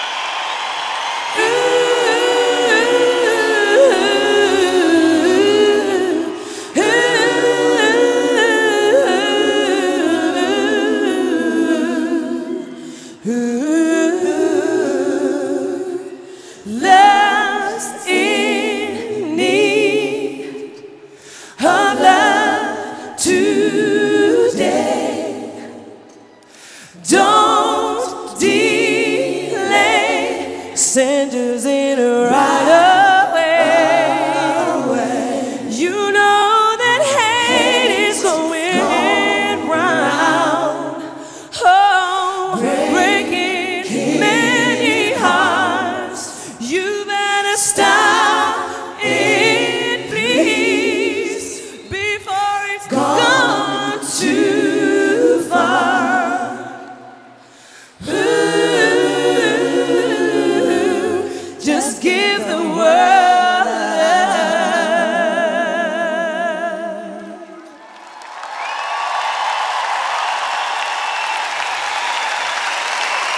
Genre: Soul.